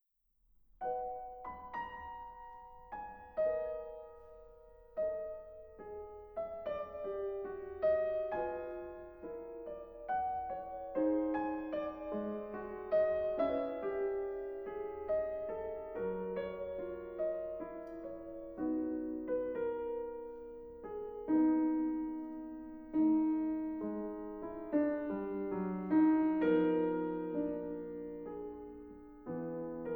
aufgenommen Herbst 2021 im Tonstudio